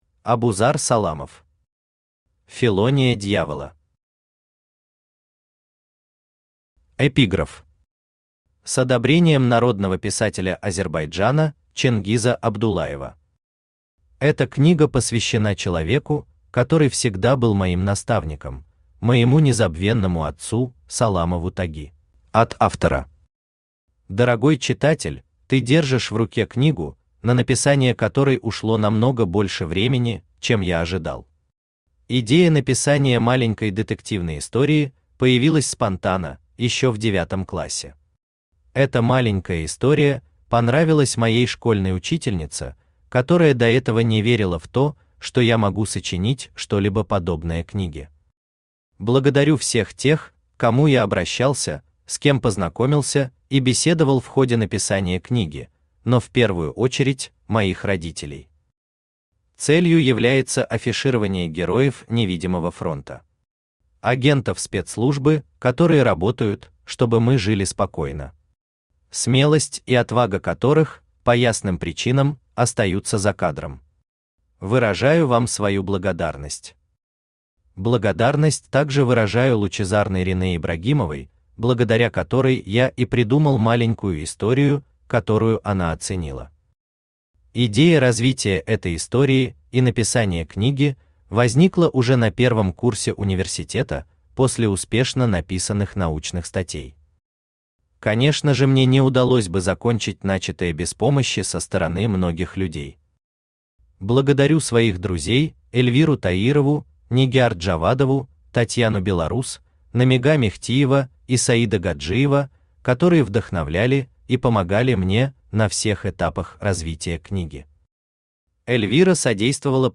Читает: Авточтец ЛитРес
Аудиокнига «Фелония дьявола».